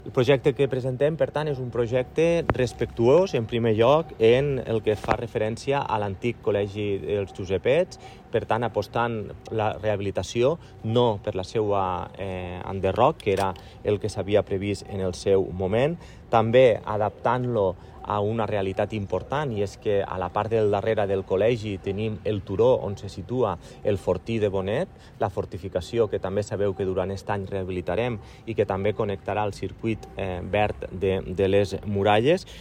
L’alcalde ha subratllat que el projecte és respectuós amb el patrimoni de la ciutat, ja que aposta per la rehabilitació i reutilització d’espais històrics per donar-los nova vida i millorar l’oferta esportiva i cultural de Tortosa.